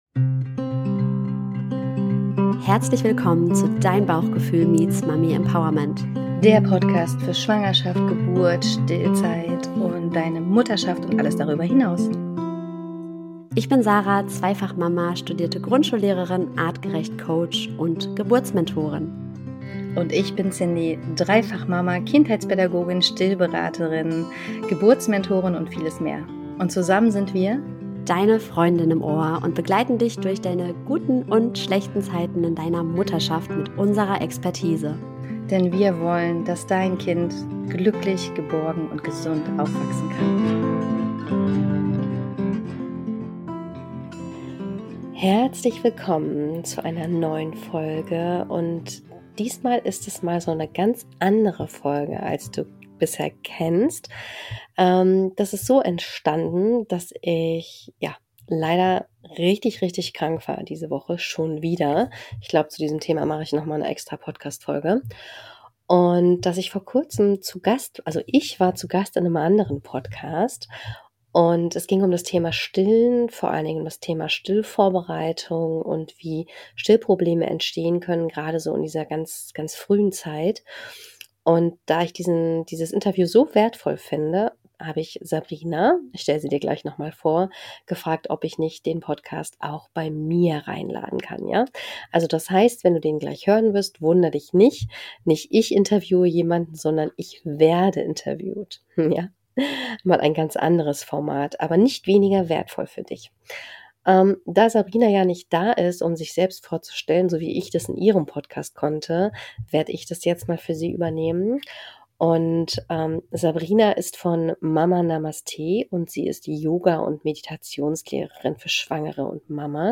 Diese Folge ist etwas anders als sonst, denn diesmal bin nicht ich diejenige, die interviewt – sondern ich werde interviewt!